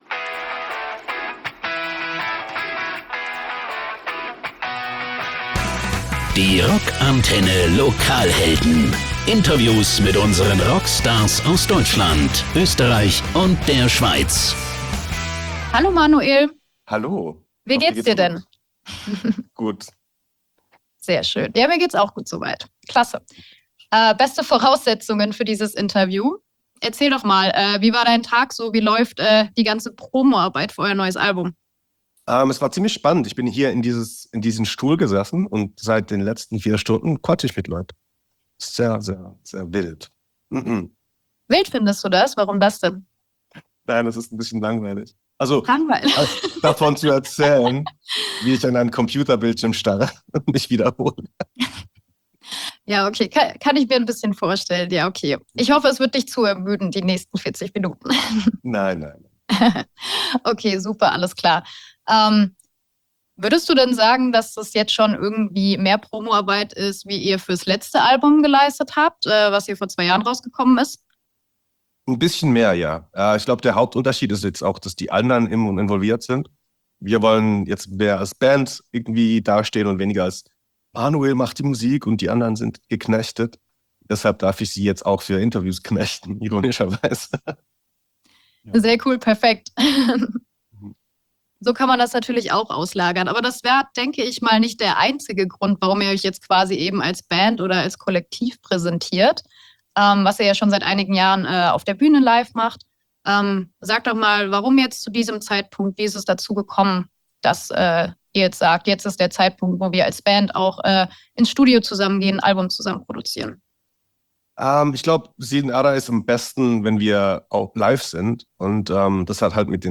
Wir haben mit Bandleader Manuel Gagneux gesprochen. Wie man als Experimental-Band immer noch überrascht und was passiert, wenn hungrige Interviewer auf hungrige Künstler treffen, erfahrt ihr im exklusiven ROCK ANTENNE Interview.